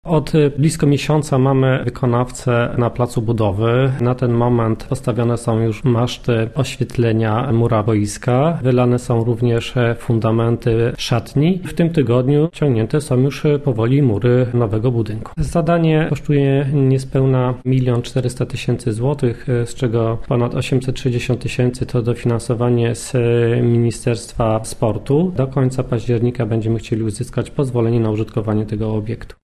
– mówił wójt gminy Pątnów, Jacek Olczyk.